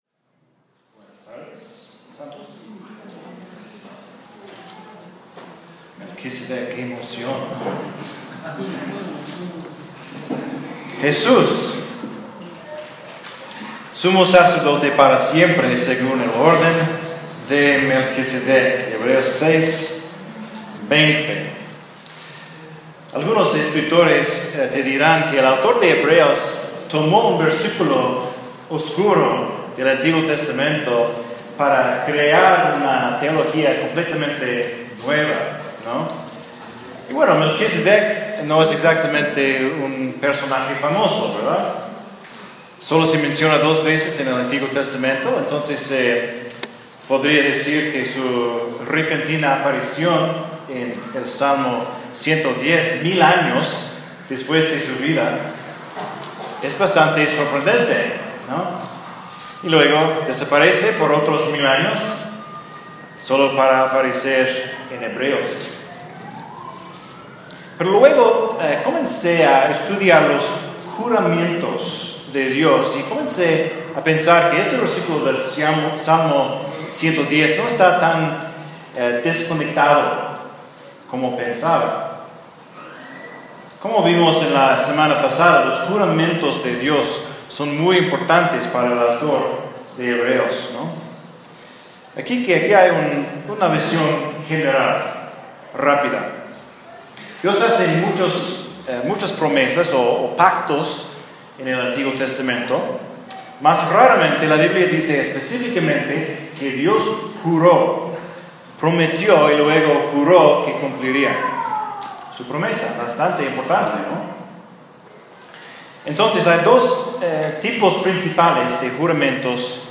Melquisedec (sermón)
Un sermón de Hebreos 7:1-19 – ¡los misterios de Melquisedec!